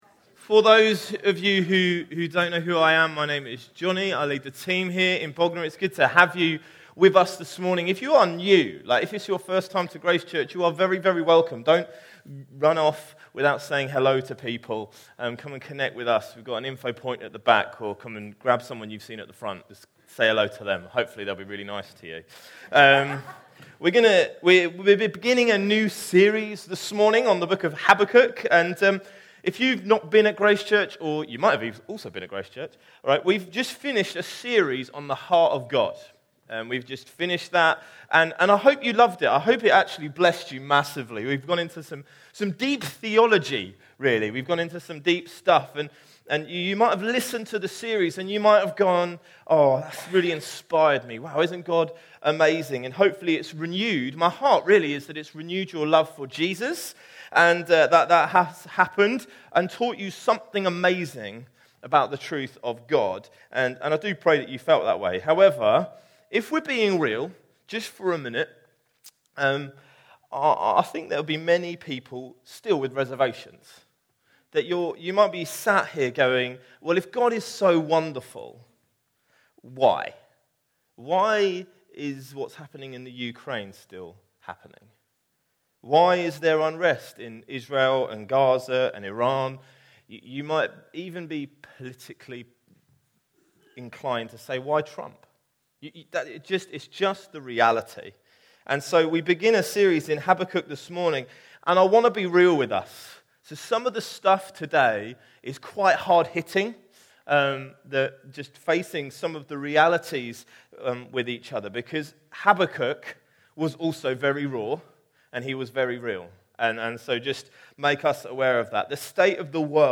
Bognor Regis